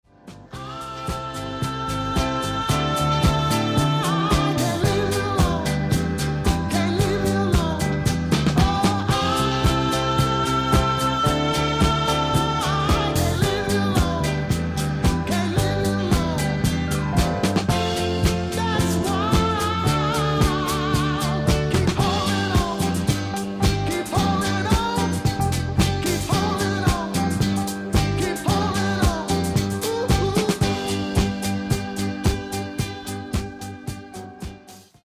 Genere:   Disco | Funky | Miami Sound